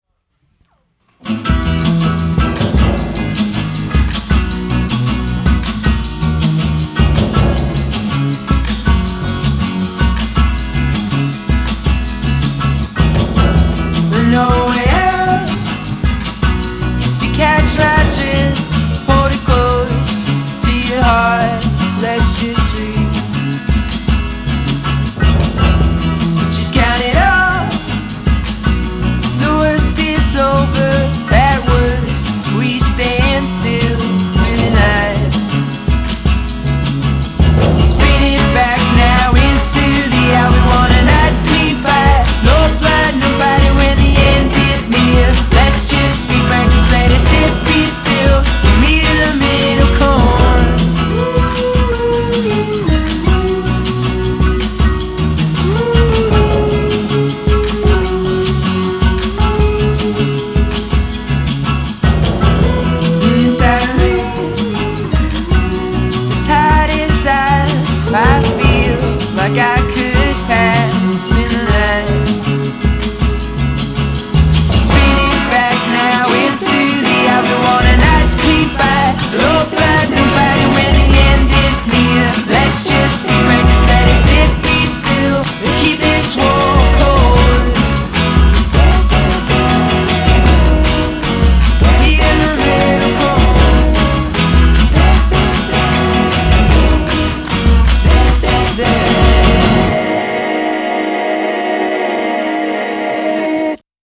explosive pop